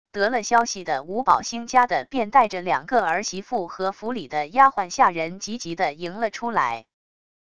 得了消息的吴保兴家的便带着两个儿媳妇和府里的丫鬟下人急急的迎了出来wav音频